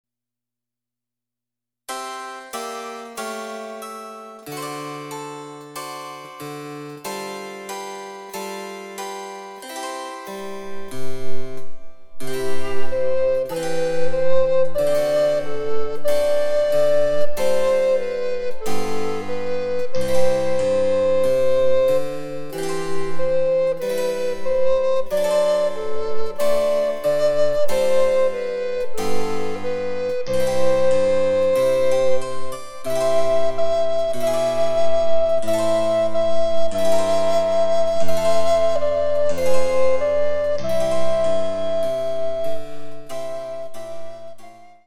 チェンバロ伴奏で楽しむ日本のオールディーズ、第４弾！
※伴奏はモダンピッチのみ。